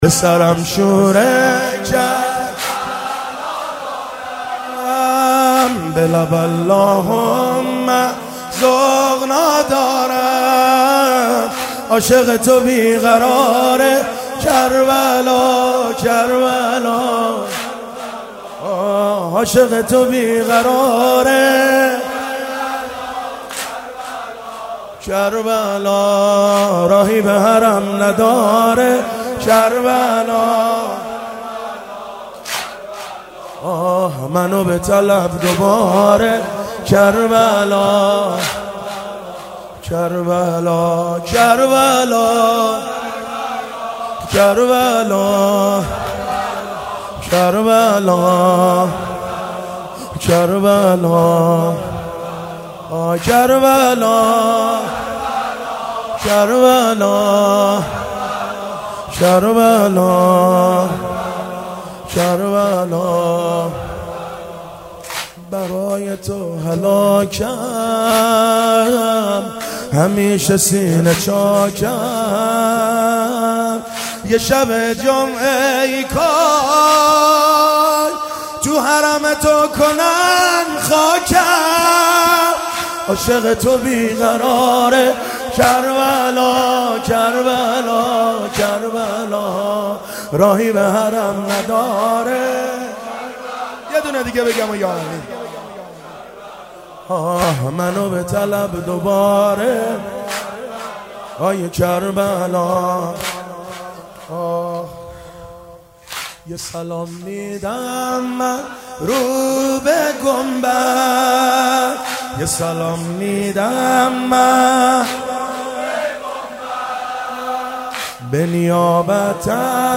مناسبت : دهه اول صفر